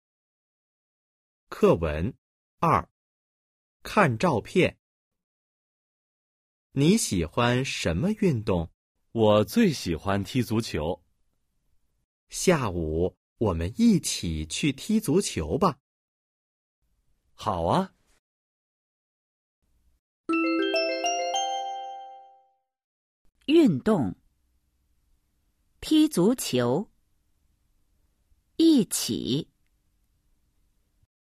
Bài hội thoại 2: 🔊 看照片- Xem ảnh  💿 01-02